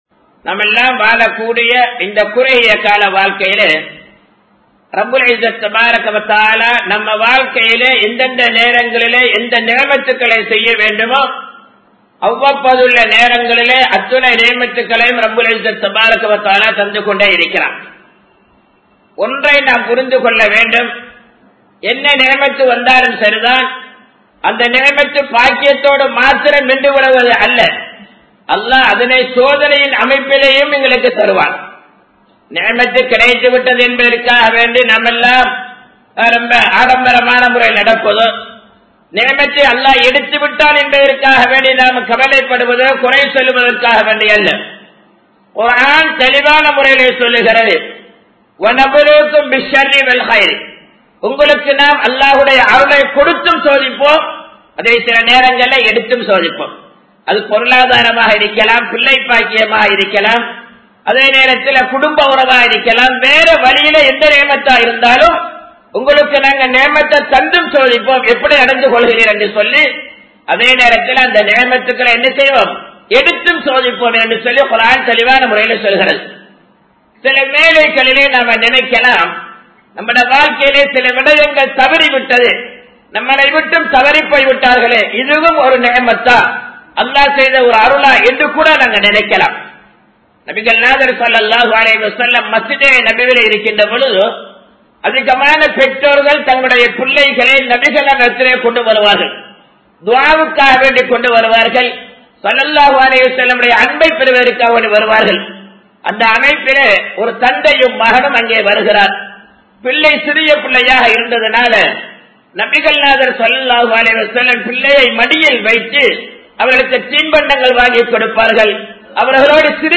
நி்ஃமத்களுக்கு நன்றி செலுத்துவோம் | Audio Bayans | All Ceylon Muslim Youth Community | Addalaichenai
Colombo 11, Samman Kottu Jumua Masjith (Red Masjith)